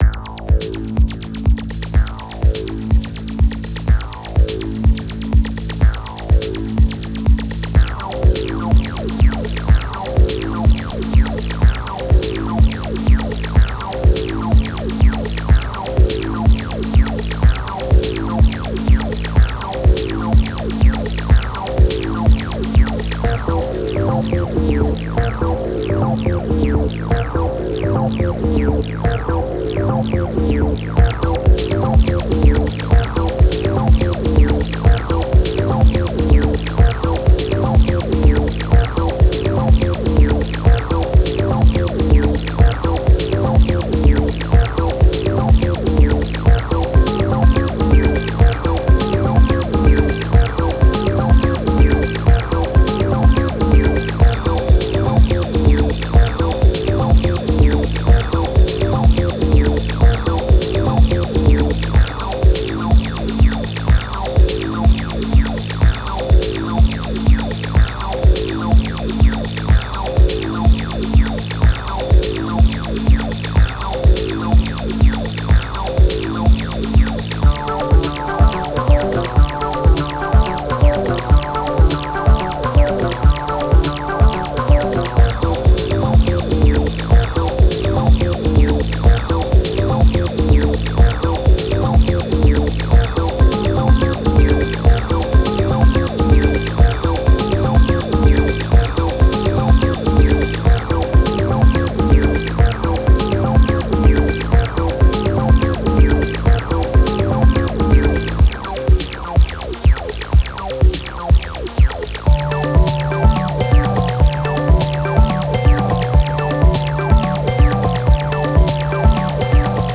Synthstuff Akai SG01V
There are some ok sounds in here, but nothing really spectaculair – the sound is a bit characterless not much alive like the Roland or EMU counterparts (JV1080 + Vintage expansion and EMU vintagekeys module for example).
Here are some mp3 sound demos just jamming with this little box, through some analog delay and my Nomium Crystal compressor, no multitracking – all sequenced in multitrimbal mode:
moody winter techno atmospheres